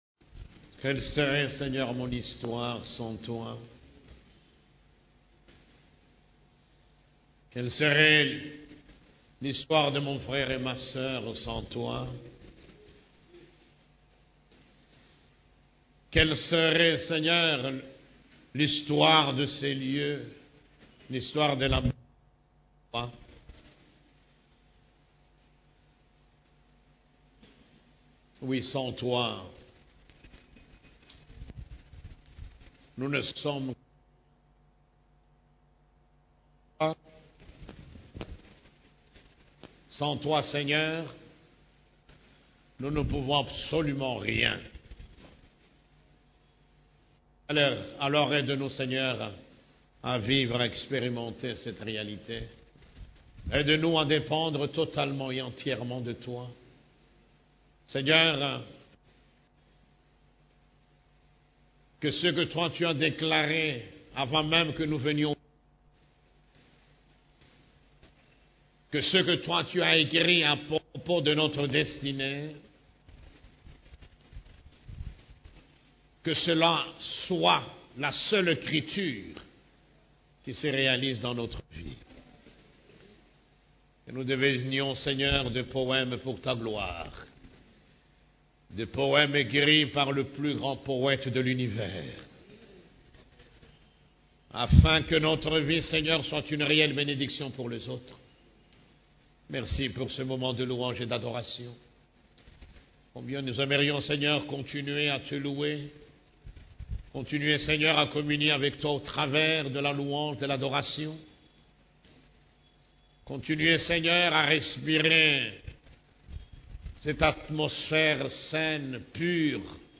CEF la Borne, Culte du Dimanche, L'Église de Jésus-Christ et sa révélation